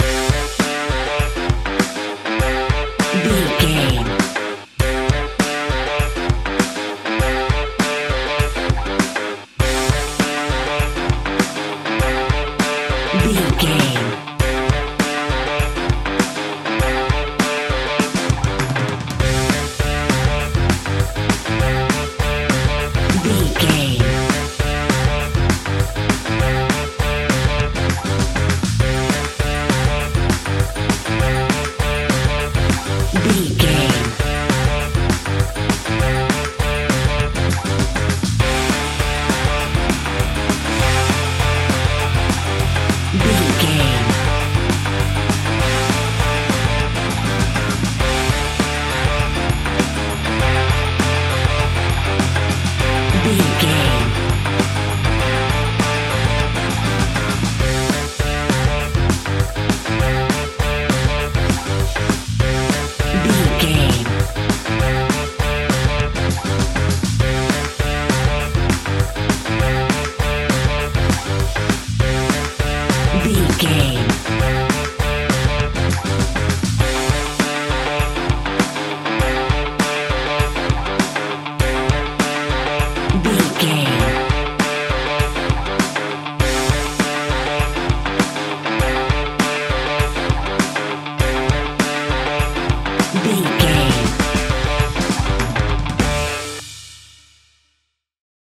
Fast paced
Aeolian/Minor
D
energetic
electric guitar
bass guitar
drums